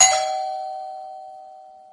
Sound Effects
New Follower Doorbell